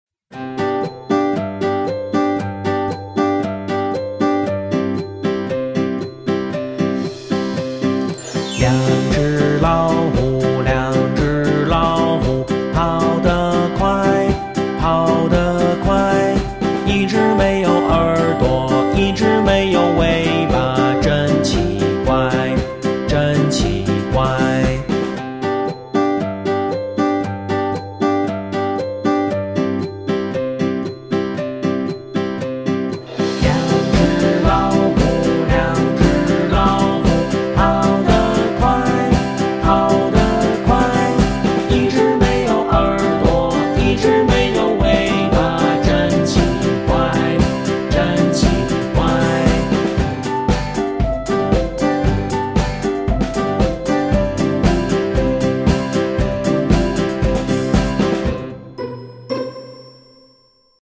Cùng hát nhé